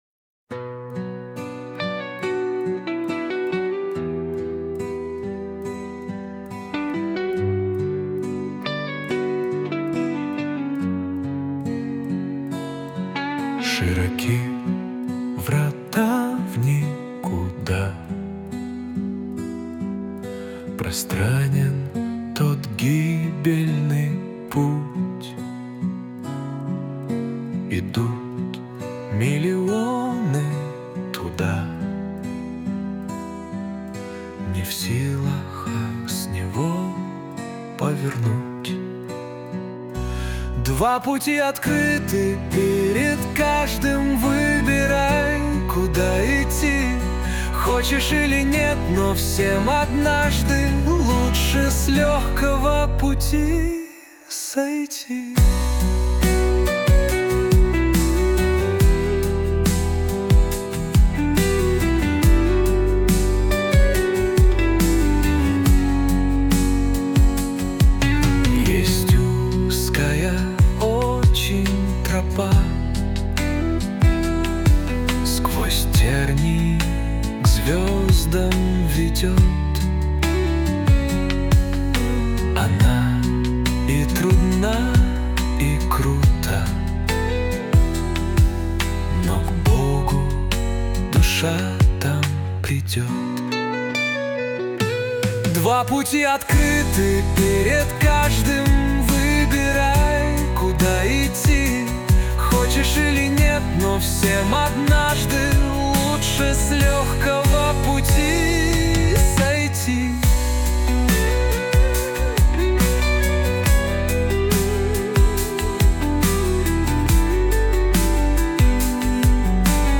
песня ai
215 просмотров 800 прослушиваний 71 скачиваний BPM: 70